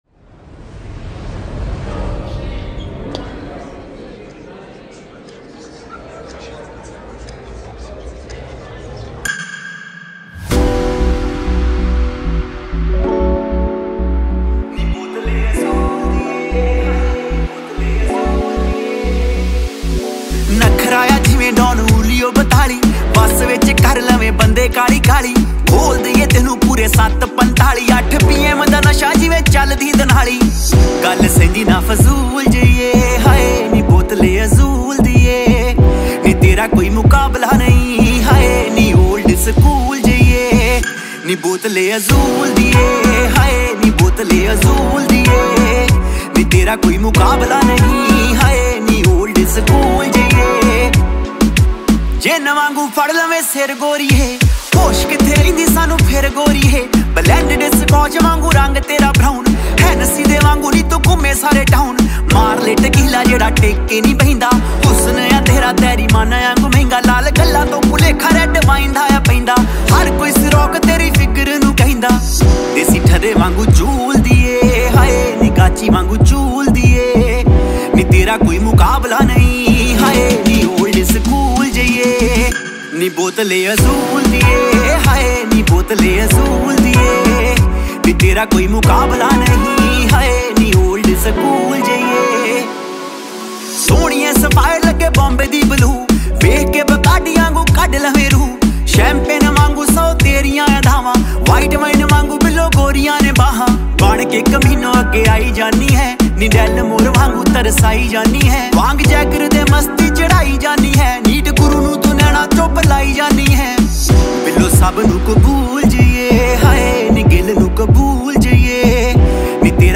vibrant beats, catchy lyrics, and global pop vibes